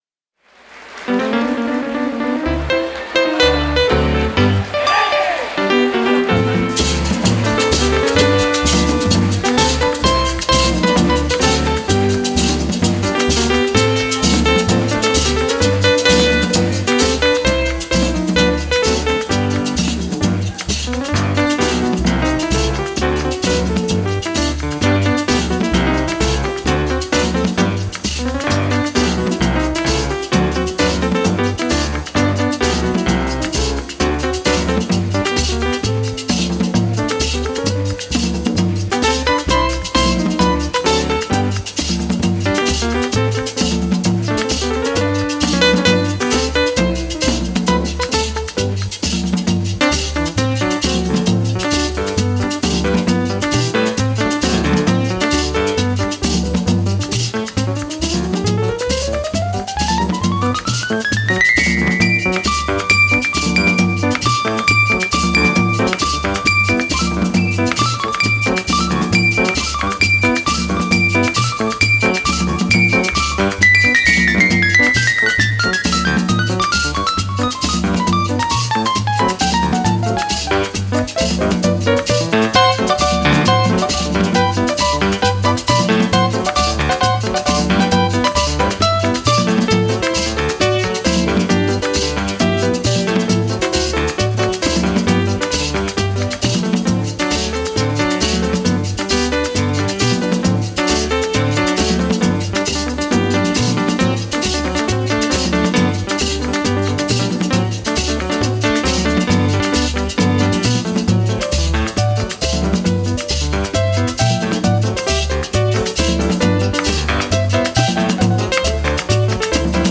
1930   Genre: Latin   Artist